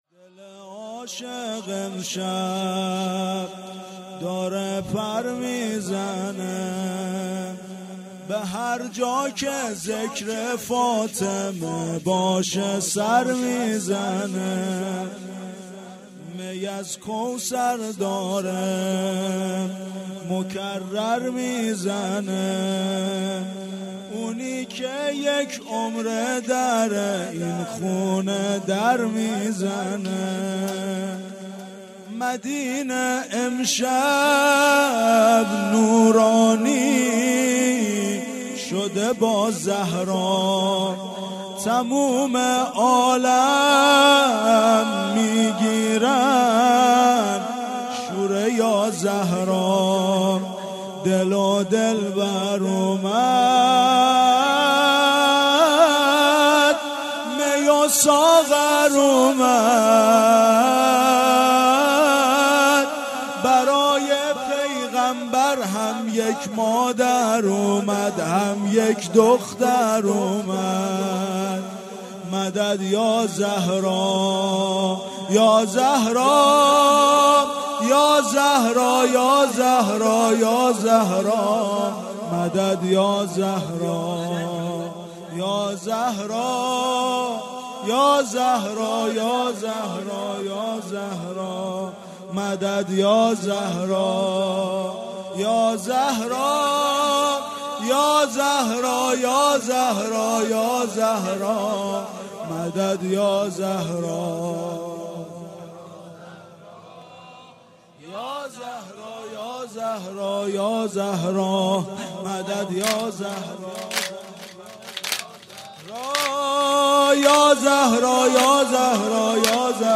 0 0 سرود - دل عاشق امشب داره پر میزنه
جشن ولادت حضرت زهرا(س)- جمعه 18 اسفند